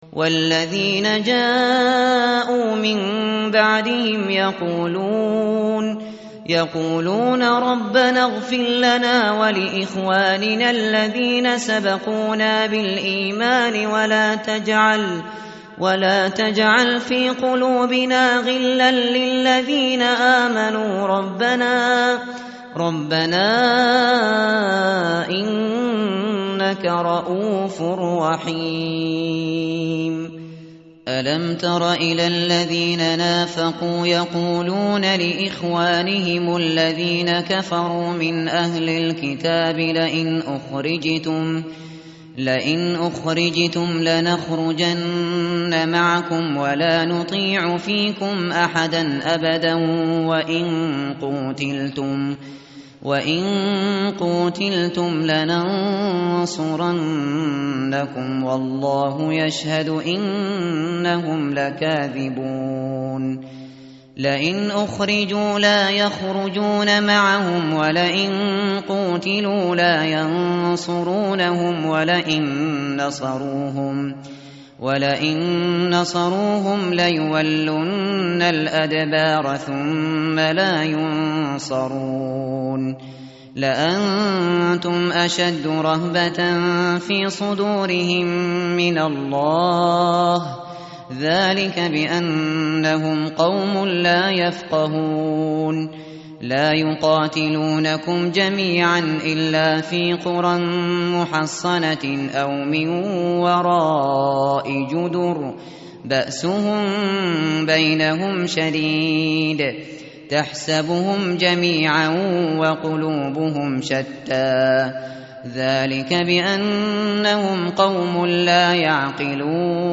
متن قرآن همراه باتلاوت قرآن و ترجمه
tartil_shateri_page_547.mp3